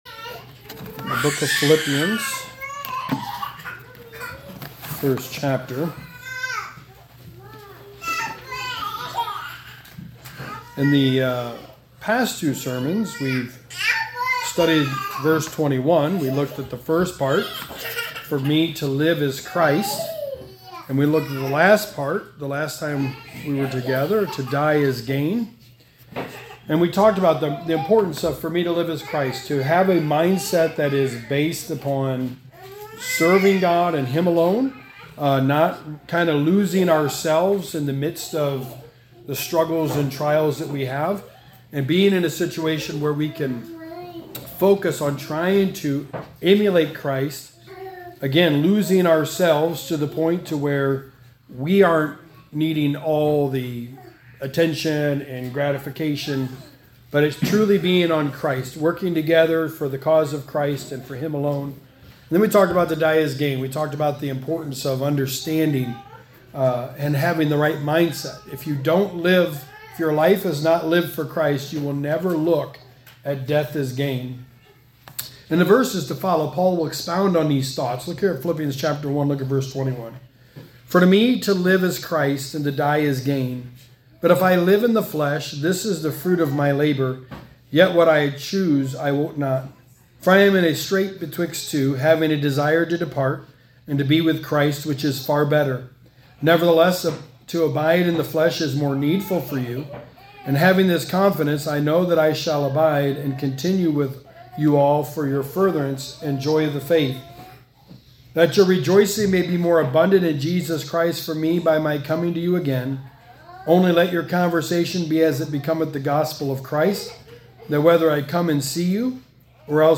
Sermon 9: The Book of Philippians: Stand Fast
Passage: Philippians 1:21-30 Service Type: Sunday Morning